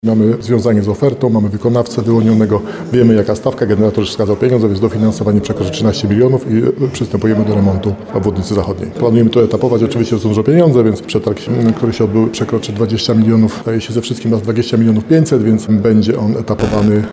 Jak mówi starosta nowosądecki Tadeusz Zaremba, uruchomione zostały dodatkowe fundusze, co oznacza, że na Sądecczyznę trafi zawrotna kwota 13 milionów złotych.